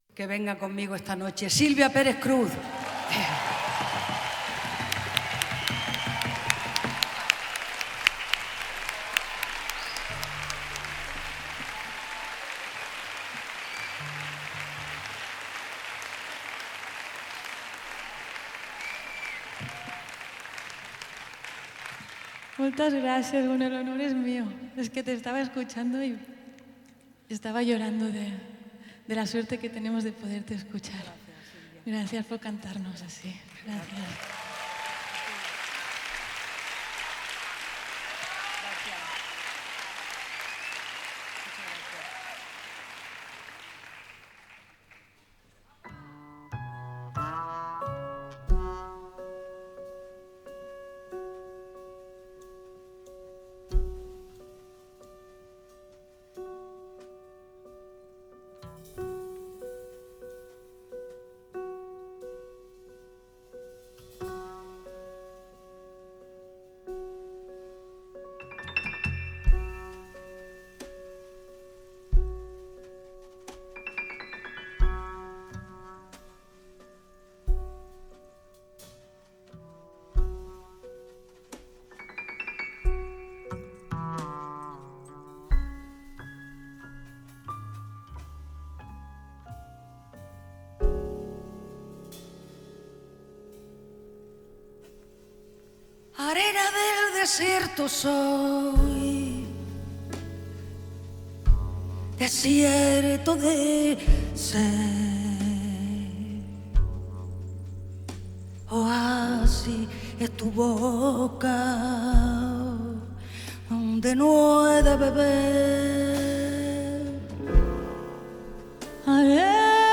En Directo
piano
contrebasse
percussions